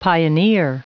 Prononciation du mot pioneer en anglais (fichier audio)
Prononciation du mot : pioneer